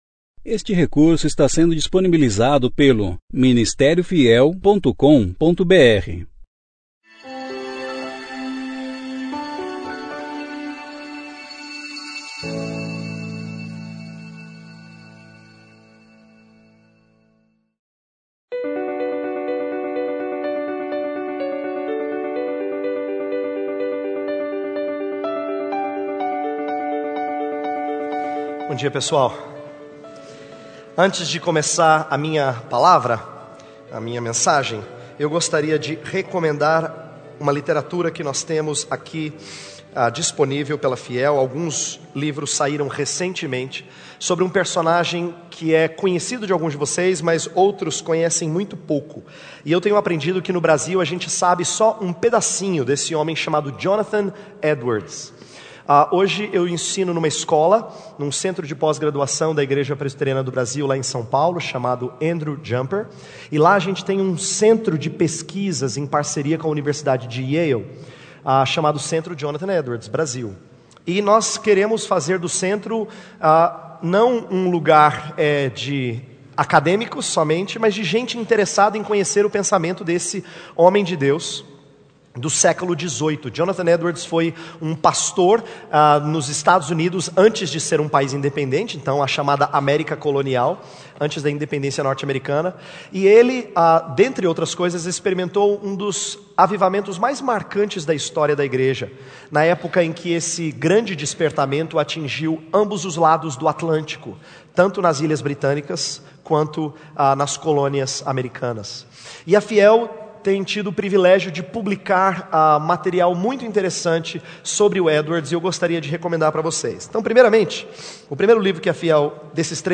13ª Conferência Fiel para Jovens – Brasil - Ministério Fiel